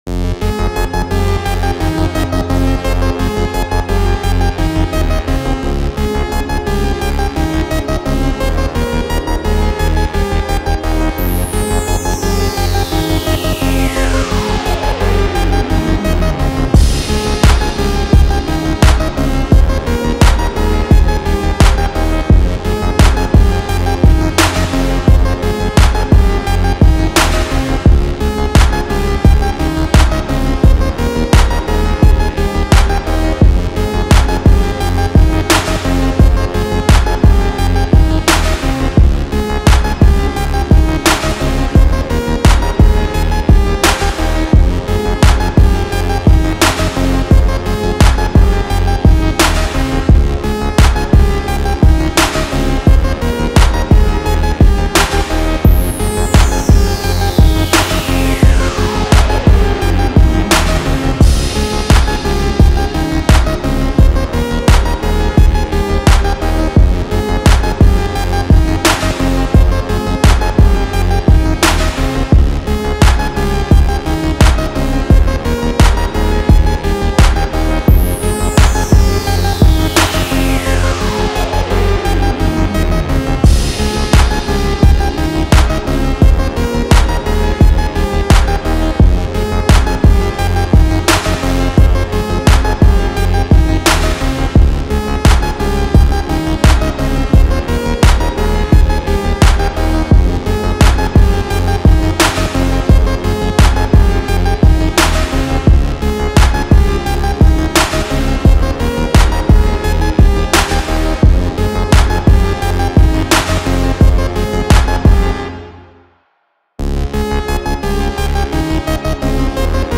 замедленная до атмосферного звучания